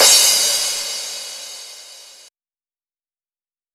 VTDS2 Song Kit 11 Female Love Me Crash.wav